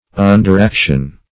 Search Result for " underaction" : The Collaborative International Dictionary of English v.0.48: Underaction \Un"der*ac`tion\, n. Subordinate action; a minor action incidental or subsidiary to the main story; an episode.